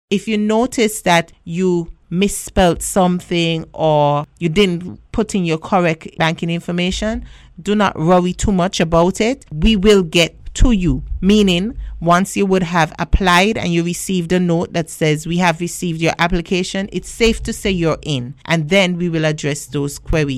Speaking in an interview on VON Radio